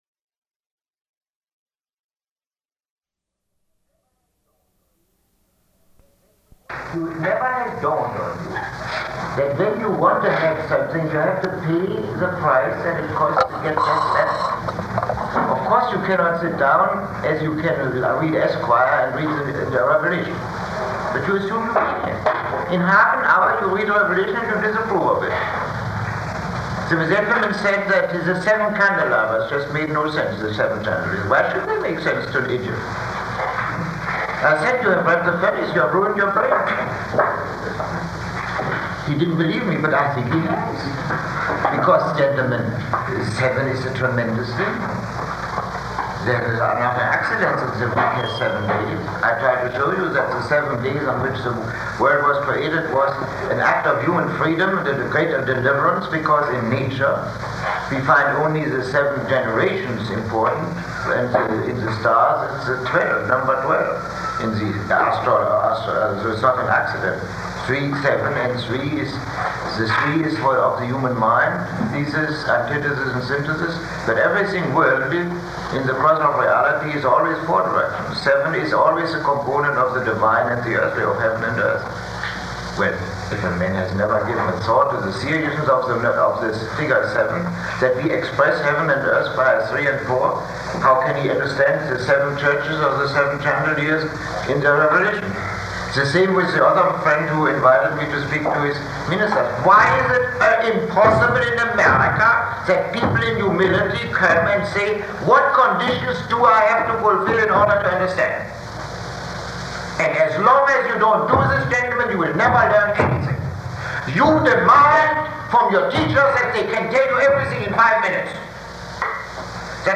Lecture 19